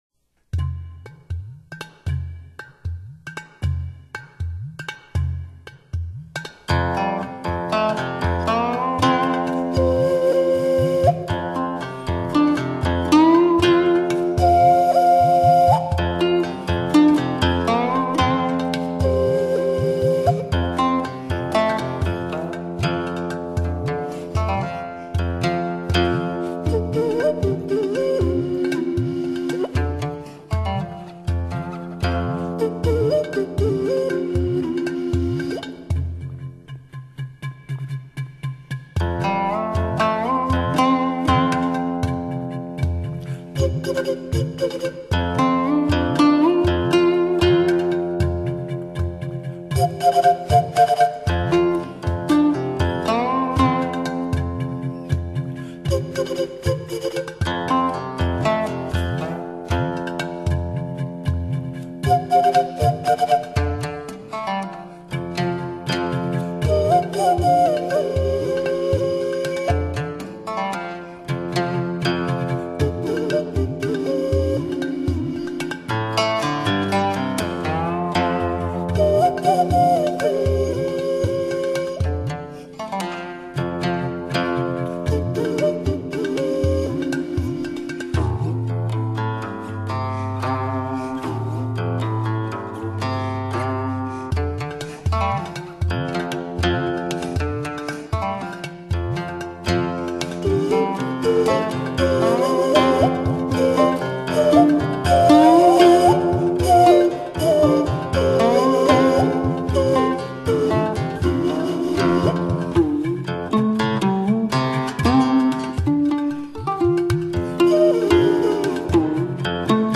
古琴
埙
专辑语种：纯音乐